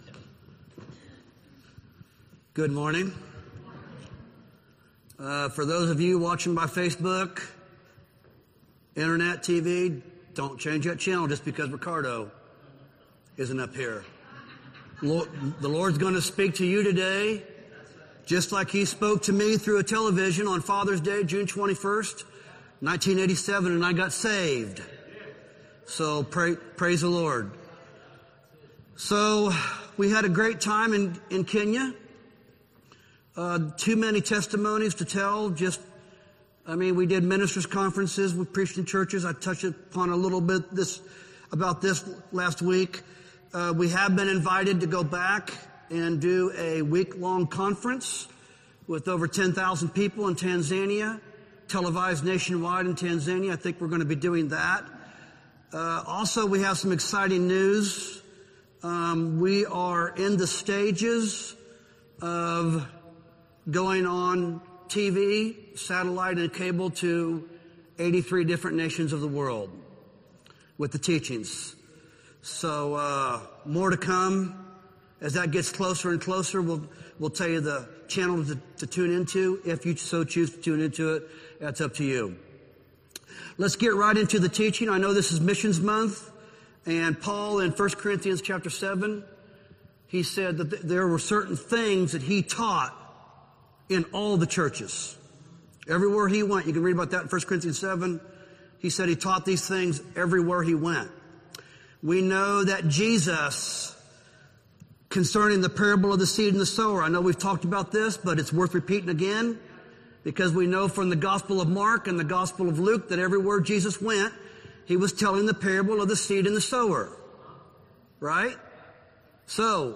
sermon9-7-25.mp3